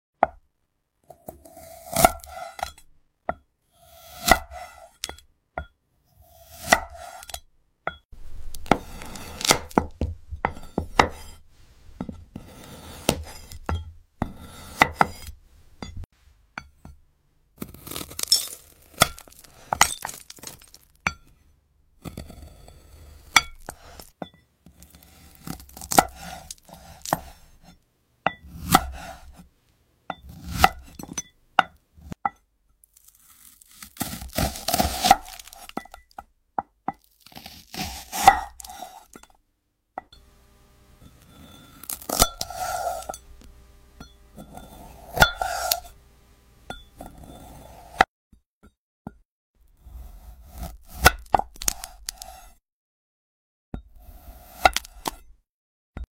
ASMR Fruit Cutting Session With Sound Effects Free Download